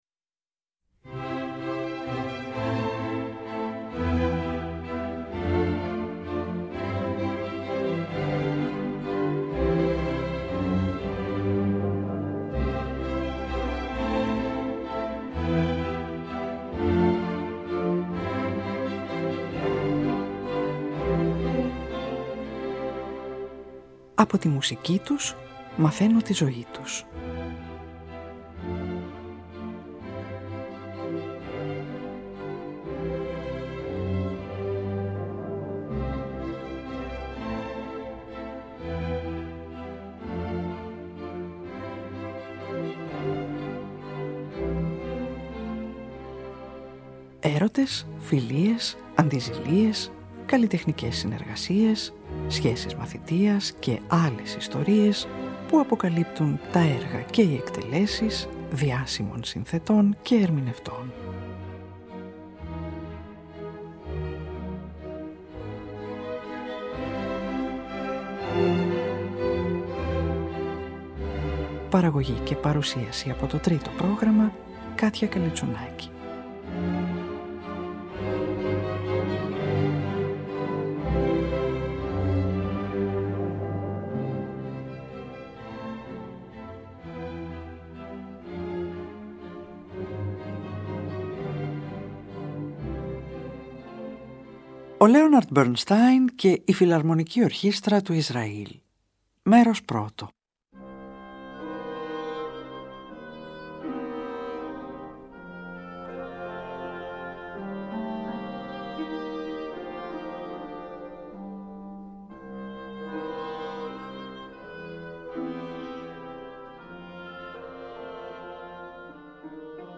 για βιολί, έγχορδα και κρουστά